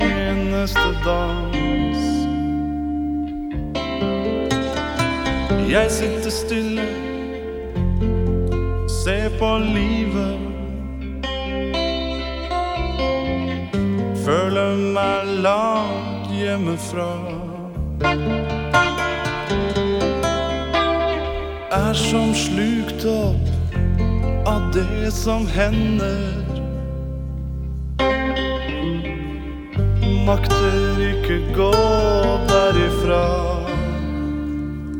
1983-08-01 Жанр: Рок Длительность